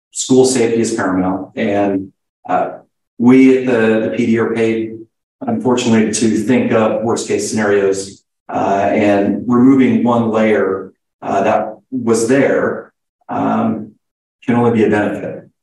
Riley County Commissioners met in Randolph Thursday evening for the third of four quarterly meetings in the county.